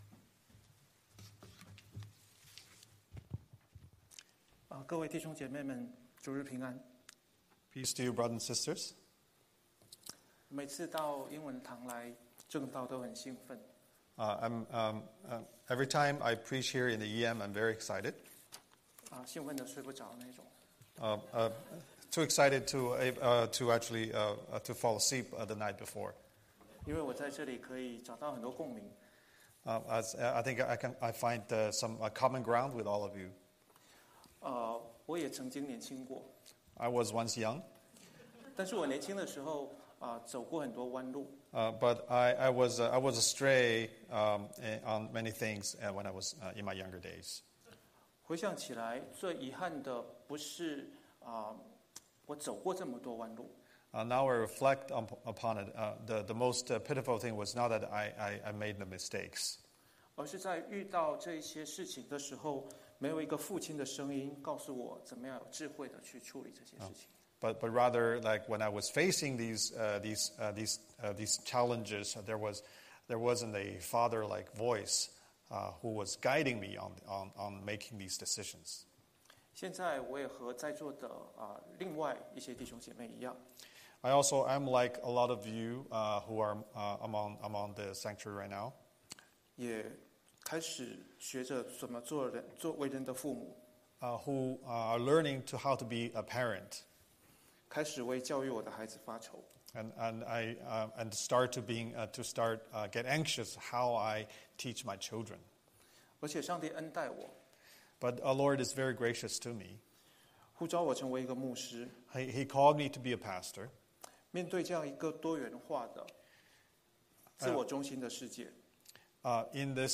Scripture: Proverbs 7:1–27 Series: Sunday Sermon